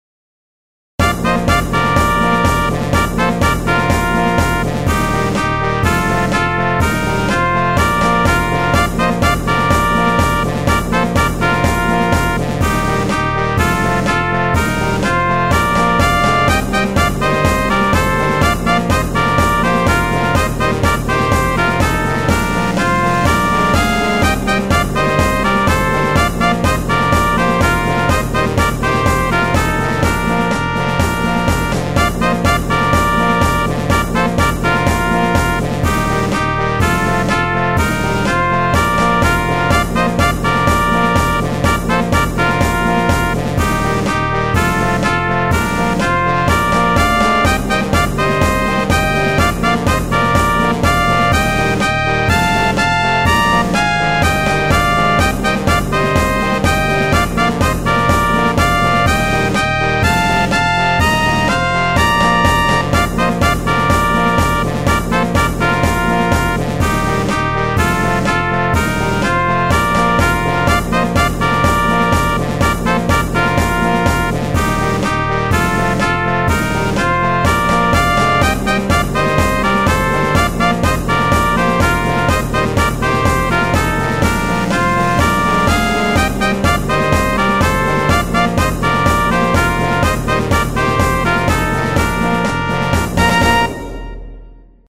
BGM
ショート明るい激しい